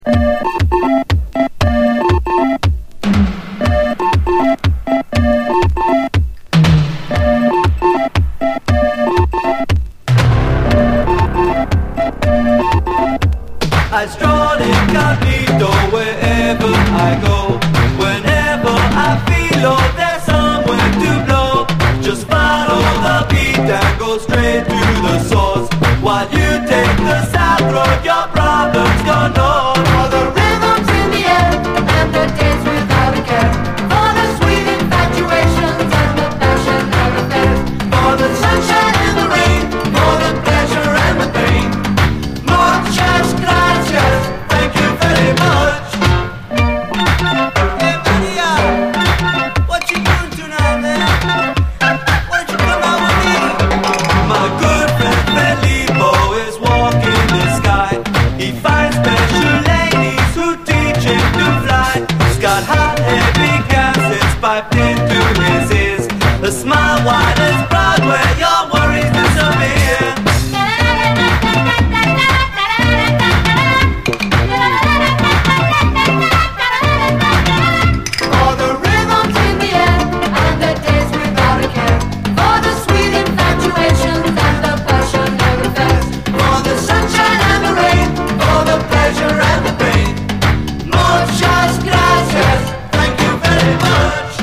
DISCO, 80's～ ROCK, ROCK, FUNK-A-LATINA
イントロの特徴的なシンセ・フレーズ！